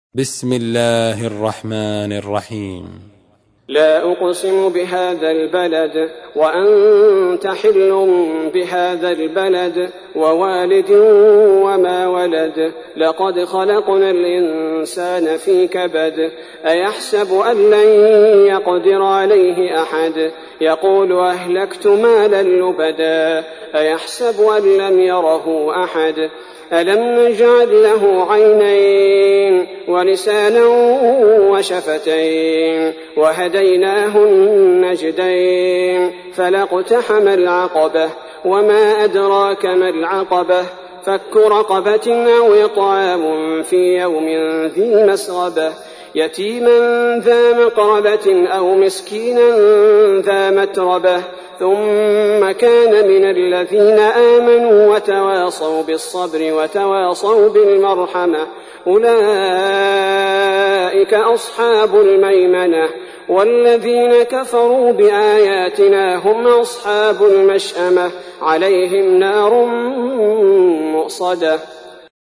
تحميل : 90. سورة البلد / القارئ عبد البارئ الثبيتي / القرآن الكريم / موقع يا حسين